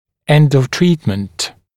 [end əv ‘triːtmənt][энд ов ‘три:тмэнт]конец лечения, окончание лечения